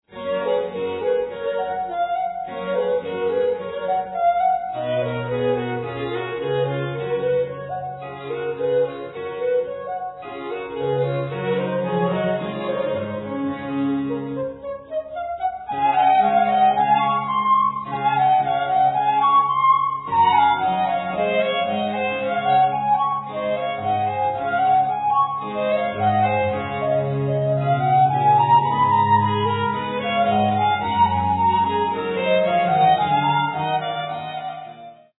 Sonata for 2 violins (or 2 flutes) & continuo in G major